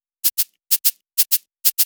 VTDS2 Song Kit 13 Rap To The Top Shaker.wav